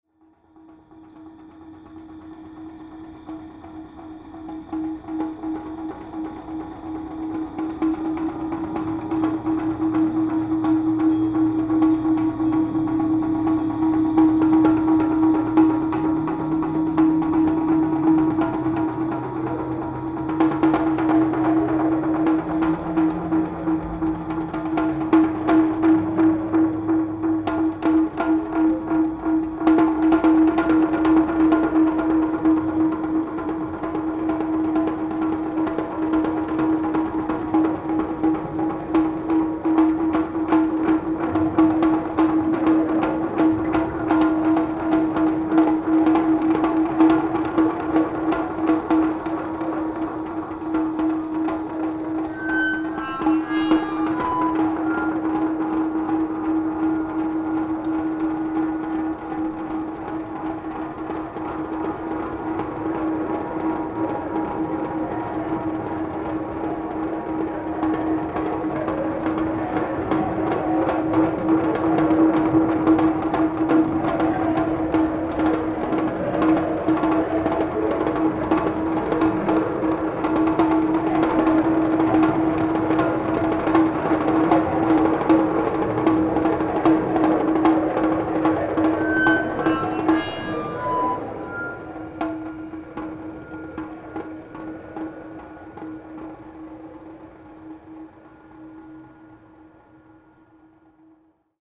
Download Buch Das kosmische Uhrwerk Rhythmen & Klangskizzen zum Buch Sample 01 Uhrwerk_2 Sample 05 Sample 04 Uhrwerk 1 Sample 06 Uhrwerk 2 Uhrwerk_8